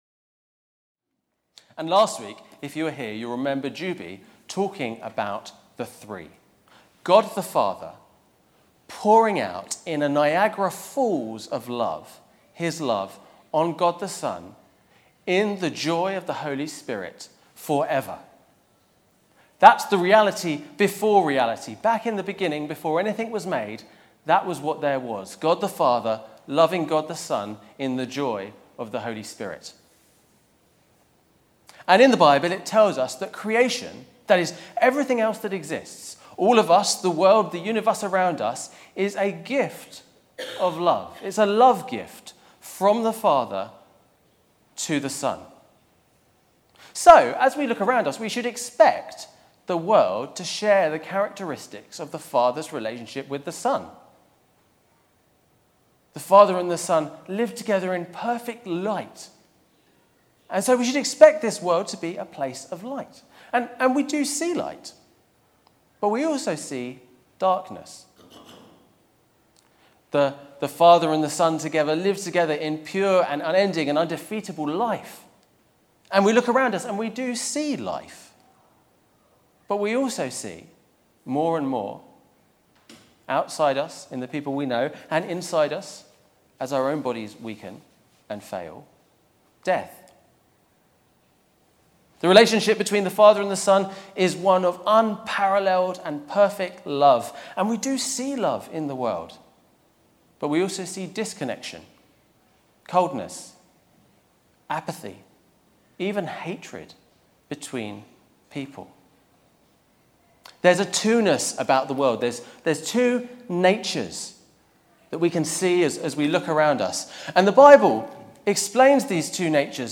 Theme: 2 - Adam and Jesus Sermon